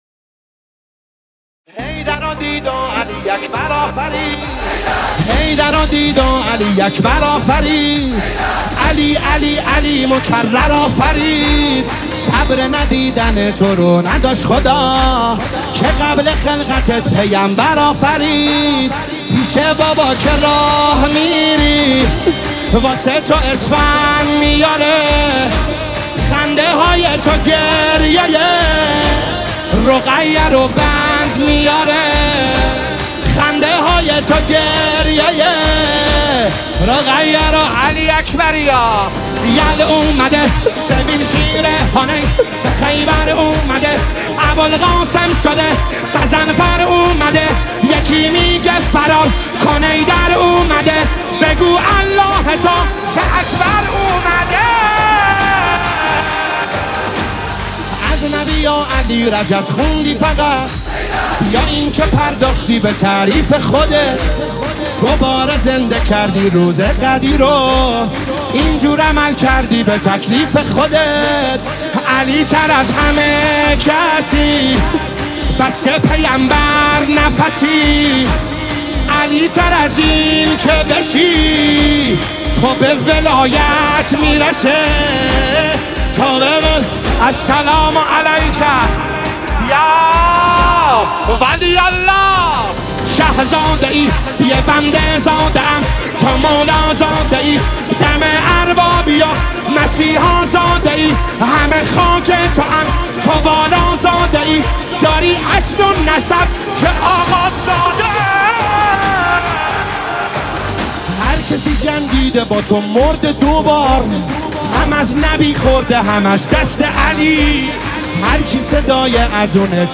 سرود و مولودی ولادت حضرت علی اکبر